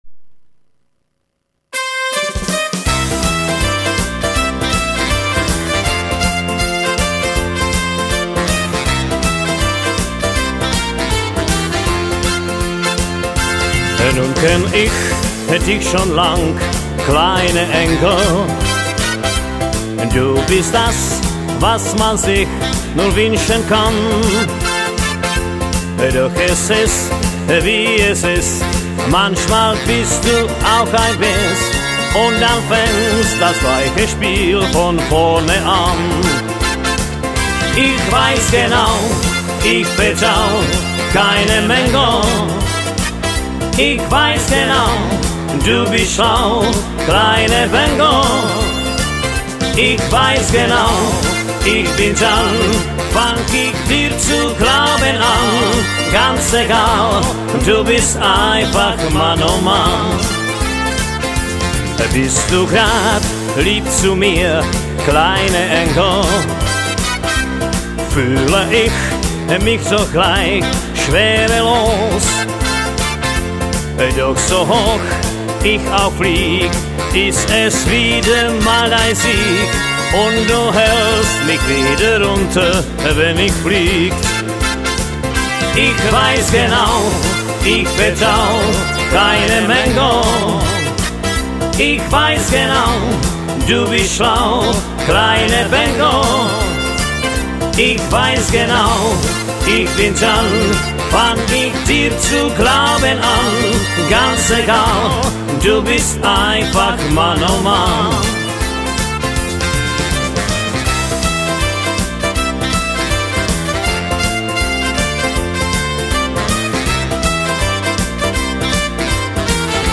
Jive